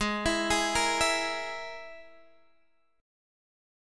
Absus2#5 chord